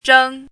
zhēng
zheng1.mp3